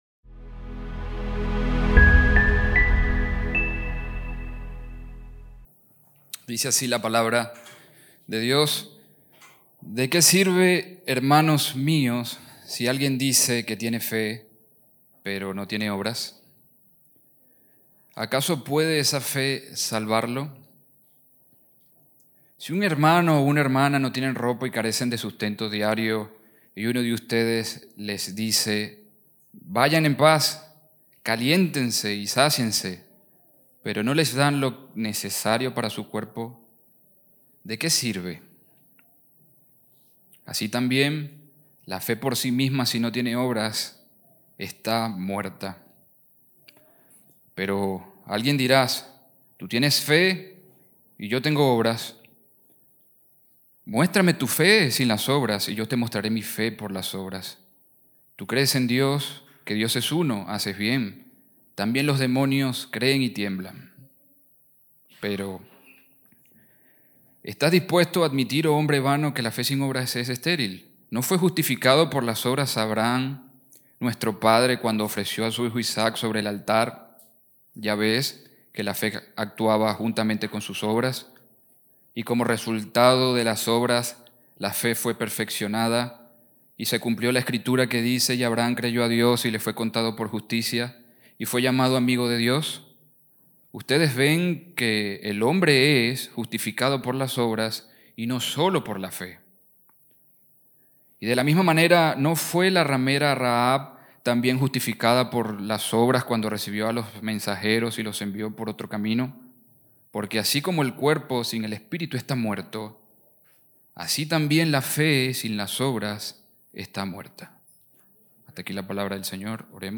Audio del sermón
Iglesia-Biblica-De-Avellaneda-esta-en-vivo-3_1_1.mp3